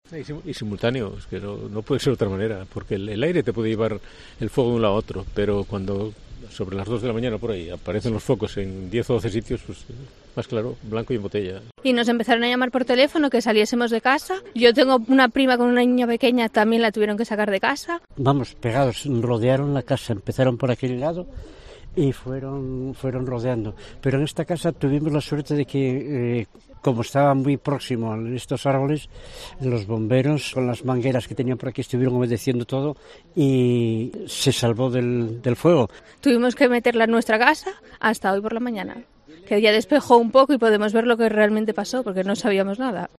Declaraciones de los vecinos del Monte Naranco desalojados en Oviedo